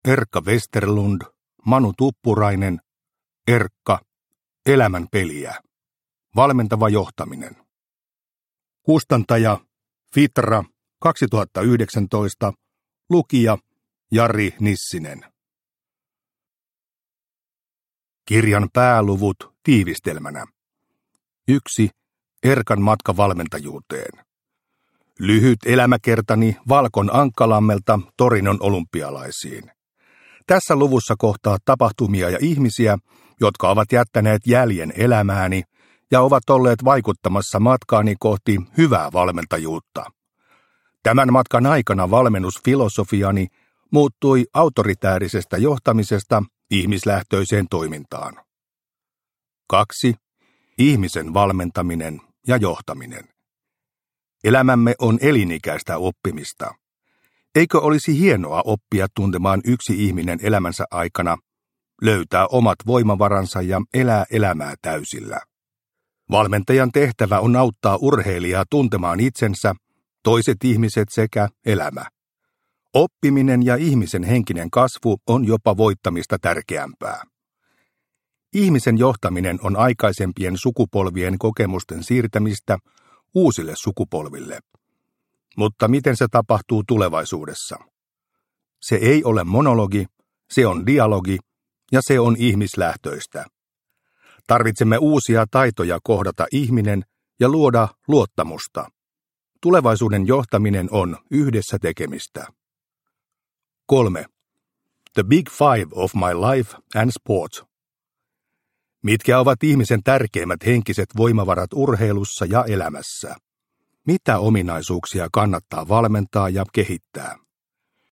Erkka – Ljudbok – Laddas ner